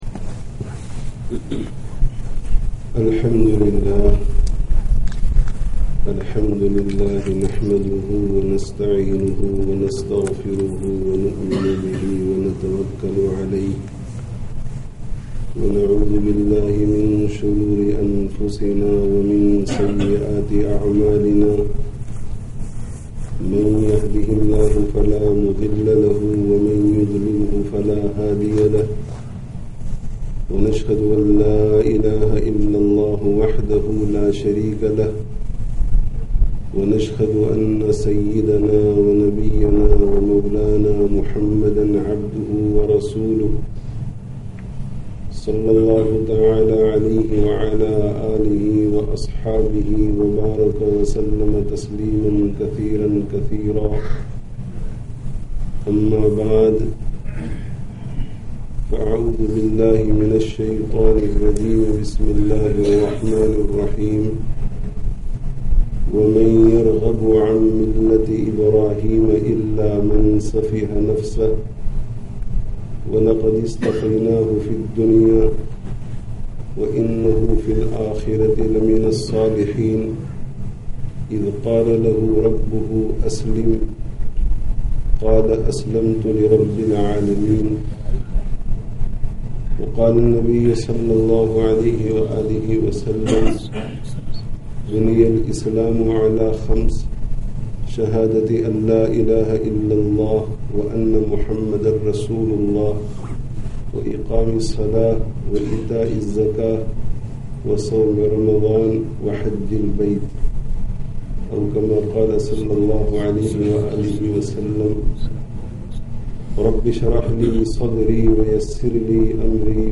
Requirements of Shukr ('Īd-ul-Adhā 1438, Masjid Mu'adh ibn Jabal, Leicester 01/09/17)